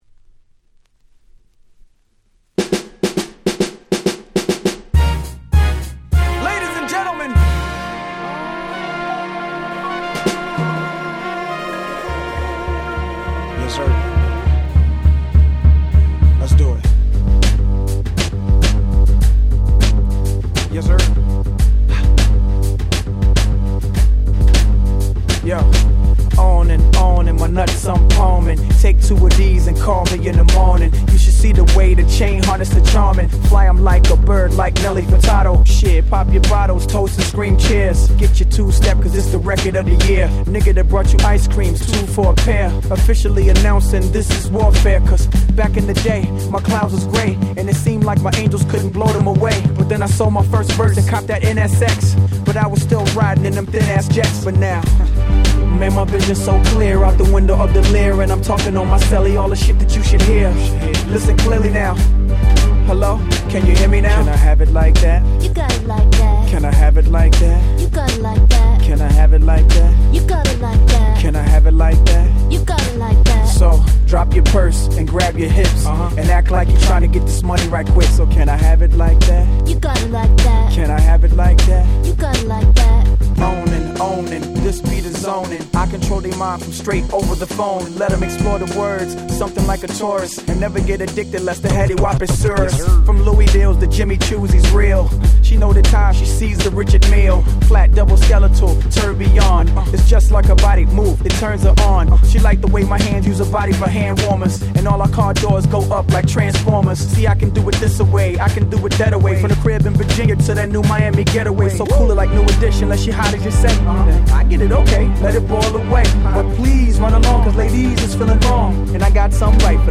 05' Smash Hit R&B !!
R&Bと言うよりはHip Hop的アプローチの強い1曲ではございますが、もうイントロから超格好良いです！！
途中でBeatが変わったりするのも格好良いです！